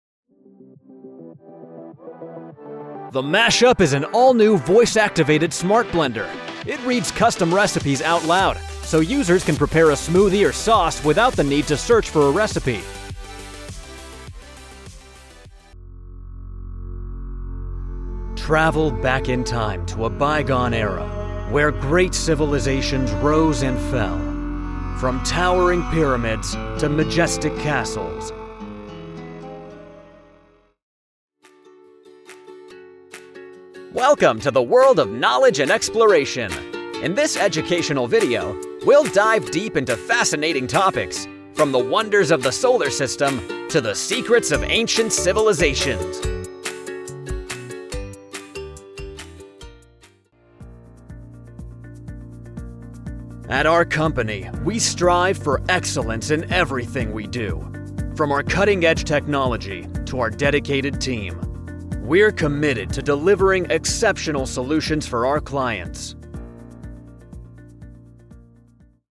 Comediante
Energizado